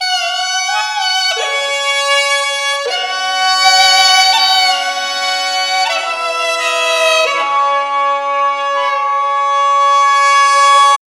CHINHORNVL-R.wav